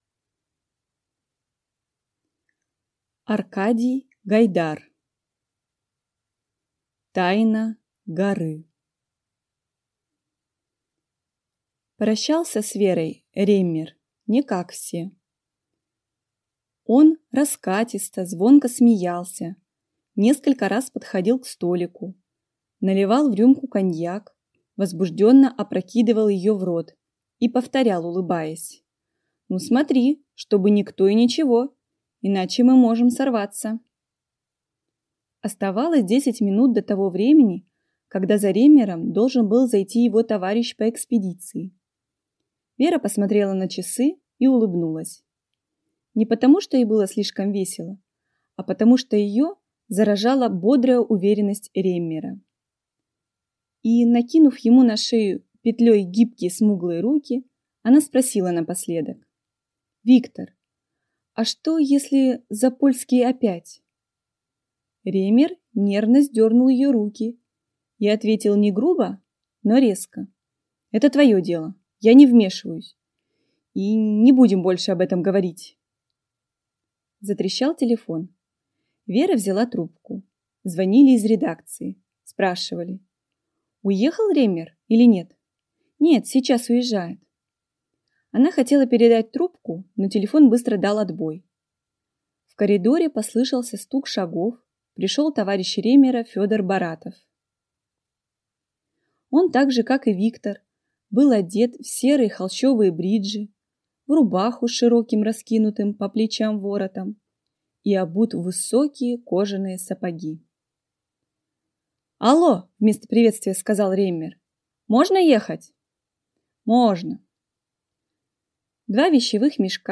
Аудиокнига Тайна горы | Библиотека аудиокниг